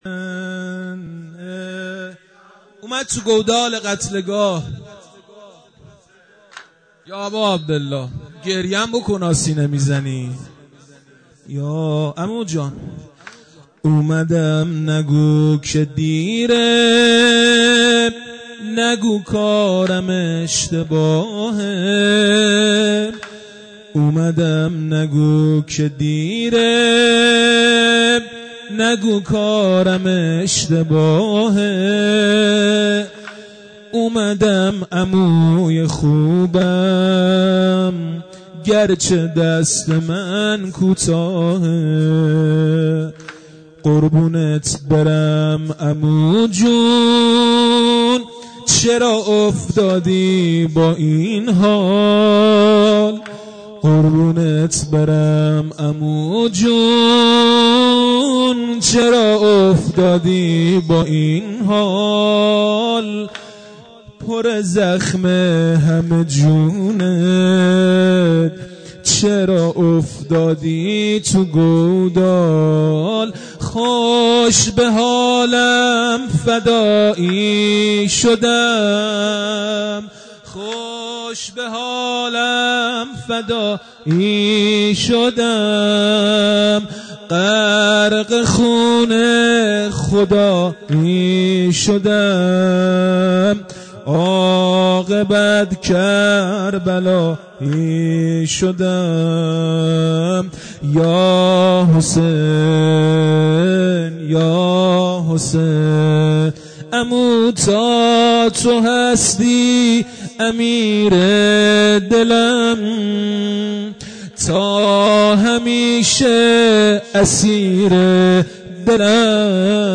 خیمه گاه - هیئت حضرت رقیه س (نازی آباد) - شب پنجم مداحی
محرم سال 1398